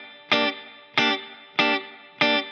DD_TeleChop_95-Gmin.wav